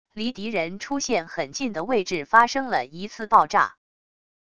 离敌人出现很近的位置发生了一次爆炸wav音频